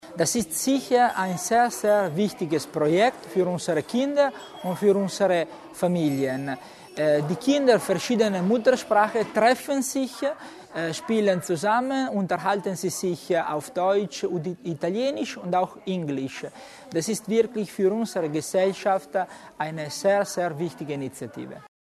Landesrat Tommasini über den Erfolg des Projektes